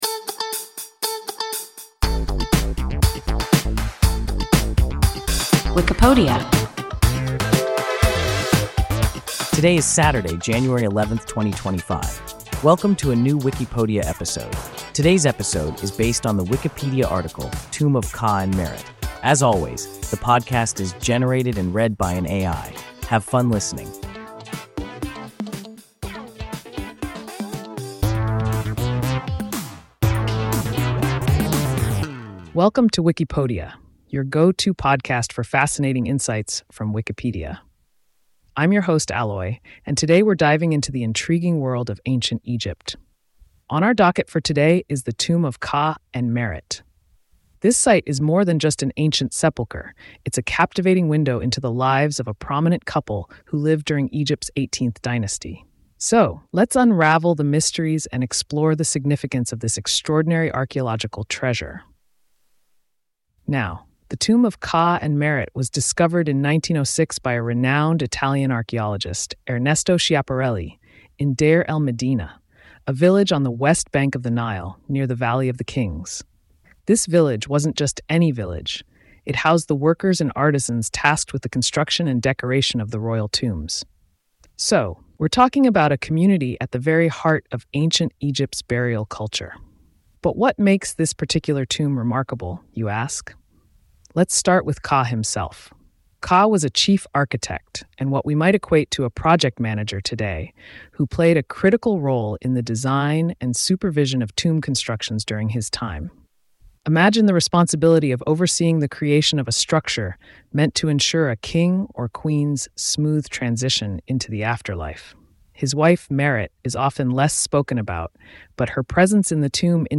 Tomb of Kha and Merit – WIKIPODIA – ein KI Podcast